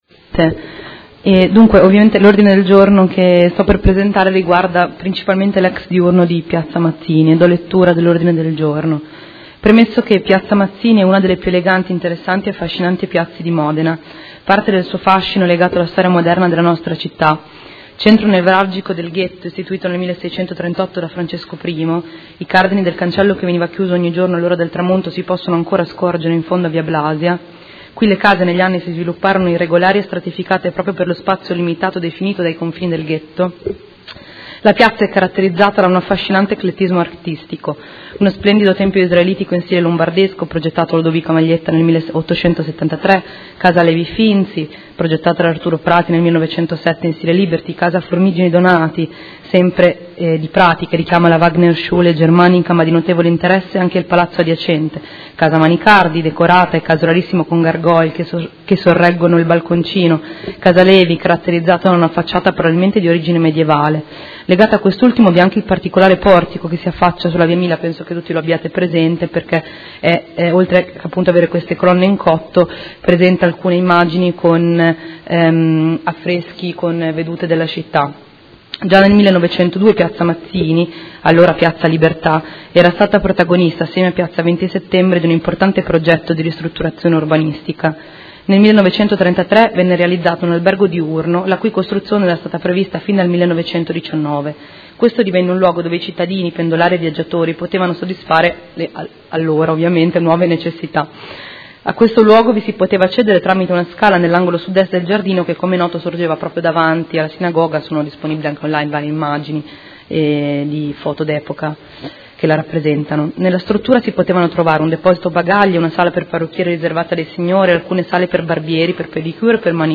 Seduta del 10/12/2015. Discussione su “Smart City” e trattazione di eventuali Ordini del giorno sull’argomento.
Audio Consiglio Comunale